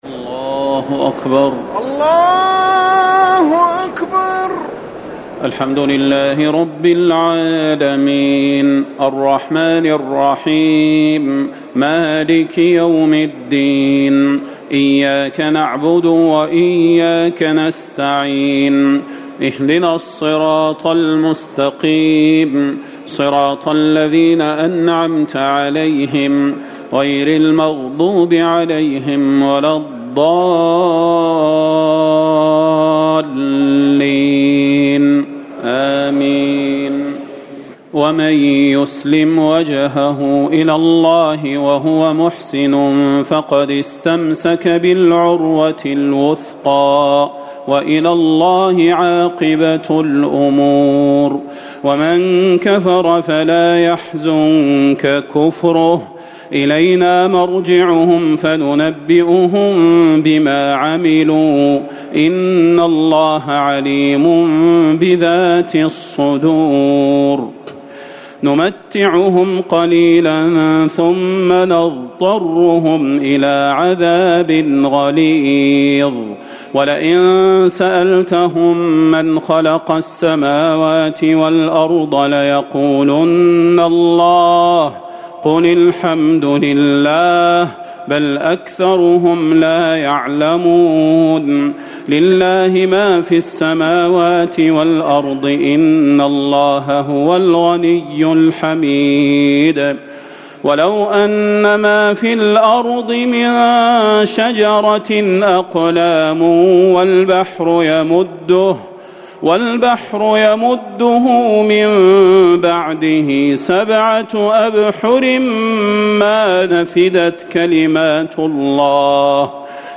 ليلة ٢٠ رمضان ١٤٤٠ من سورة لقمان ٢٢ الى الاحزاب ٣١ > تراويح الحرم النبوي عام 1440 🕌 > التراويح - تلاوات الحرمين